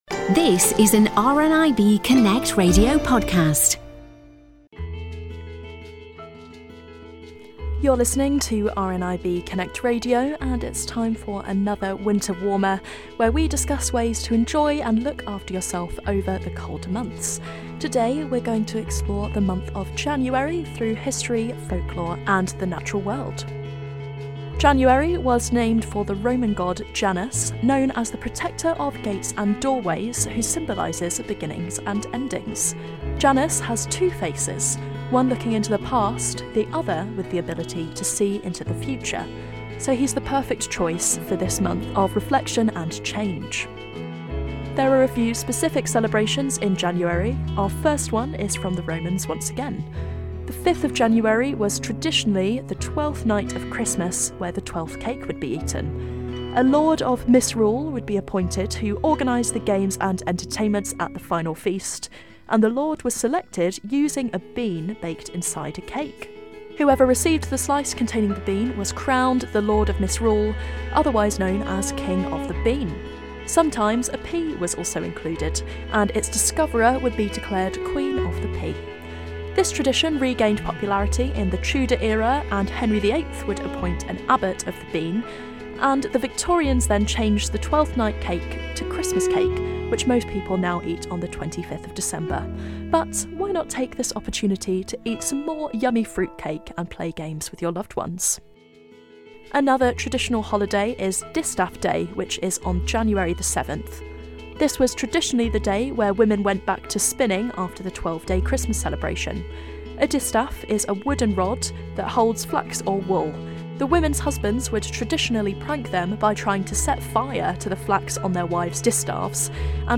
celebrating Scotland's most famous poet and listens out for bird calls.